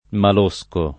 [ mal 1S ko ]